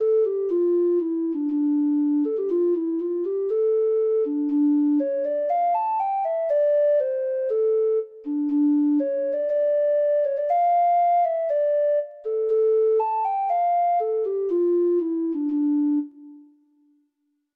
Free Sheet music for Treble Clef Instrument
Traditional Music of unknown author.
Irish